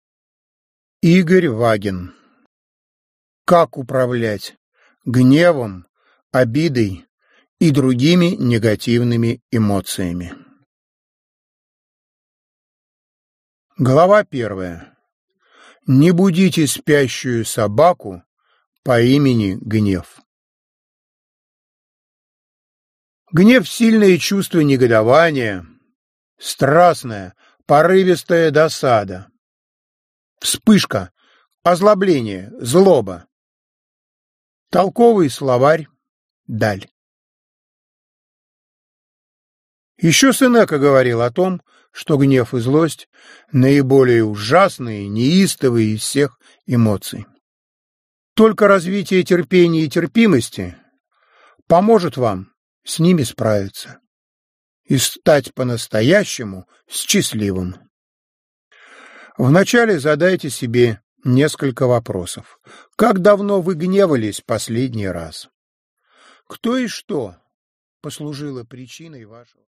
Аудиокнига Гнев, обида, месть и предательство | Библиотека аудиокниг